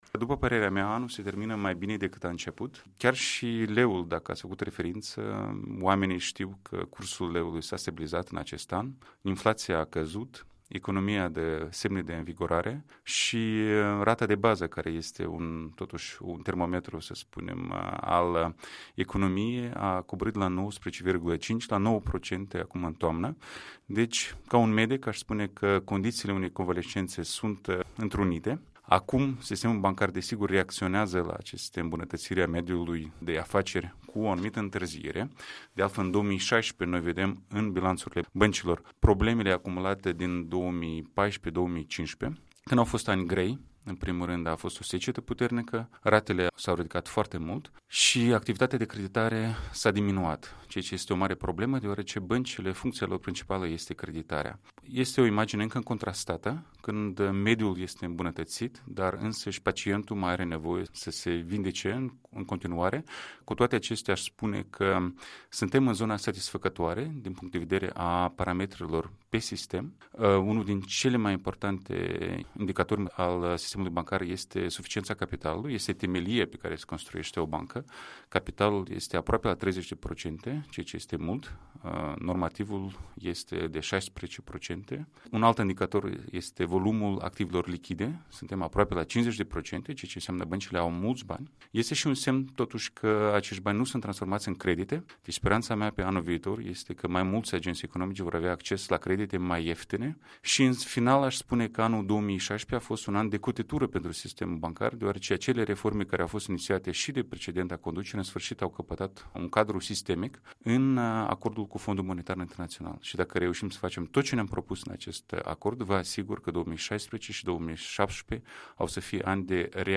Interviu cu Sergiu Cioclea